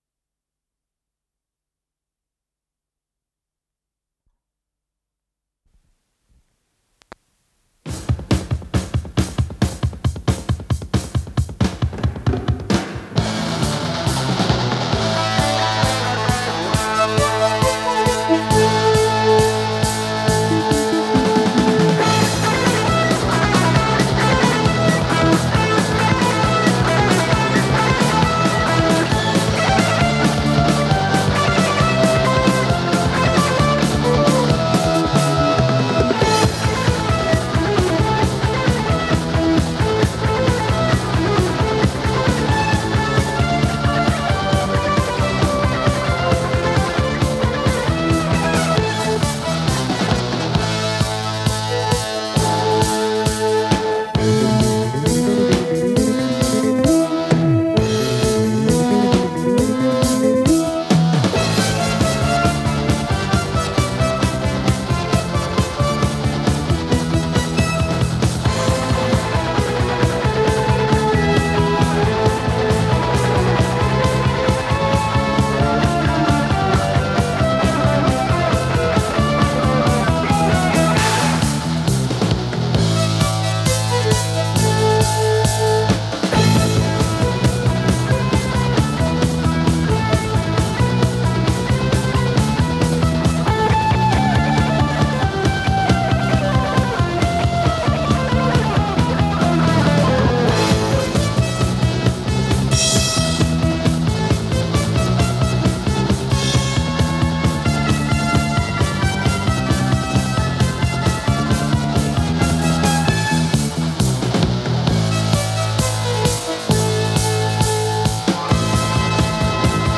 Скорость ленты38 см/с